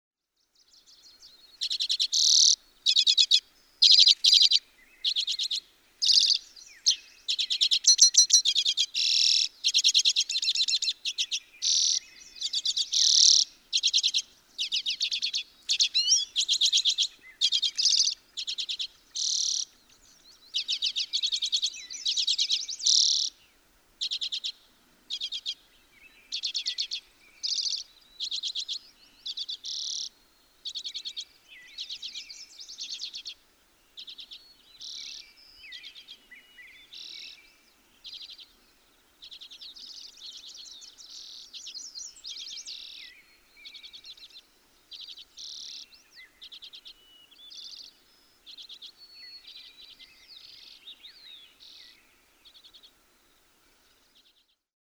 Common redpoll
As with other members of the Carduelinae subfamily (goldfinches, etc.), male redpolls sing in display flight. This one perches for 20 seconds near me, then flies off into the distance before I lose him.
Denali Highway, Alaska.
545_Common_Redpoll.mp3